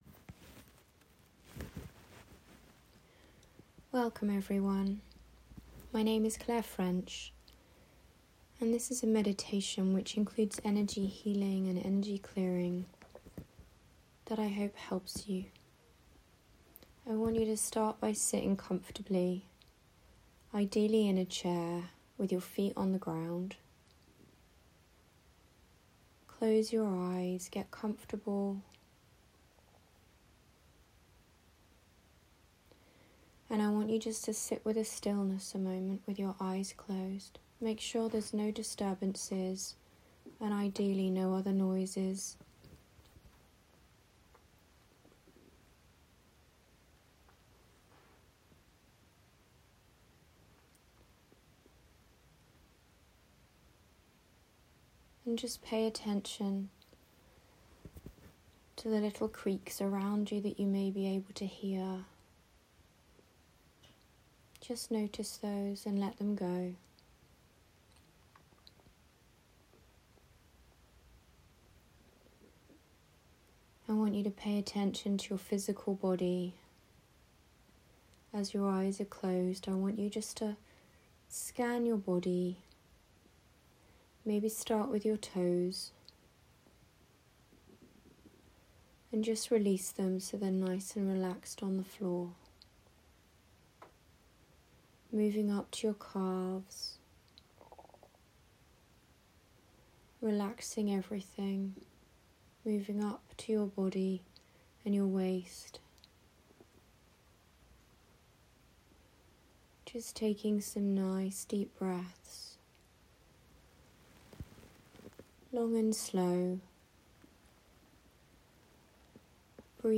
These guided meditations are free to listen to and available for you to return to whenever you need a moment of calm, healing or reset.
Meditation-January-2026.m4a